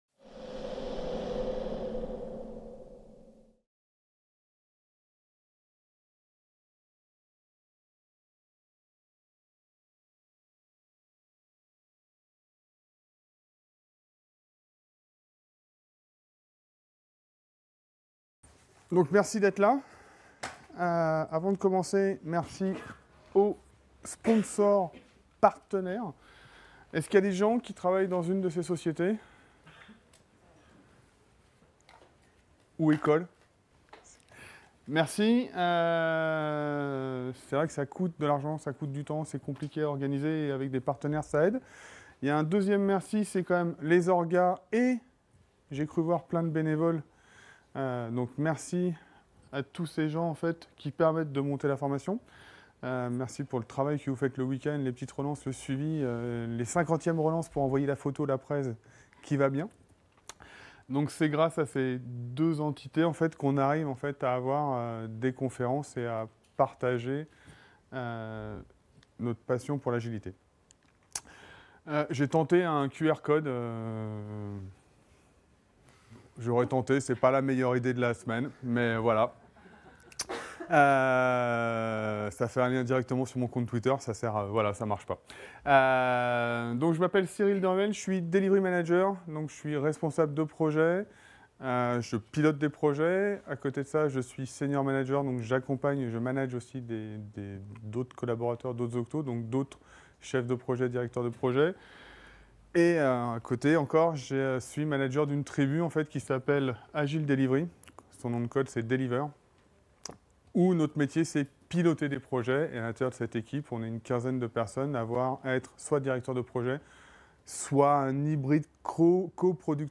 La conférence : Votre Kanban ressemble à un trafic routier ! Les tickets bougent comme des voitures sur les routes.